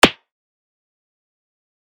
shatter.mp3